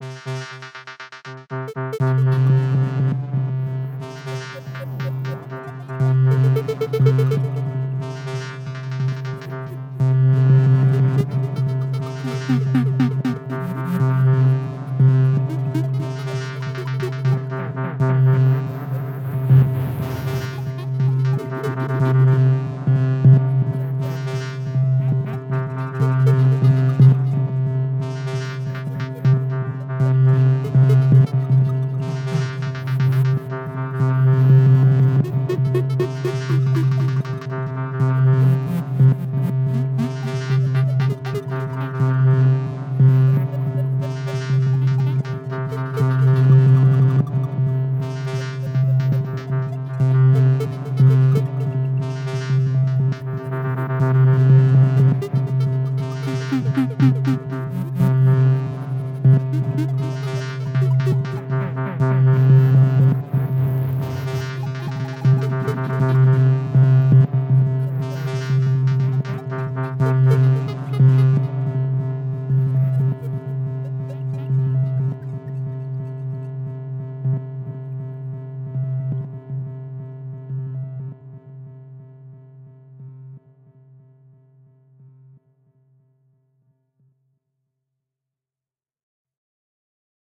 Pieza Glitch
Música electrónica
melodía
sintetizador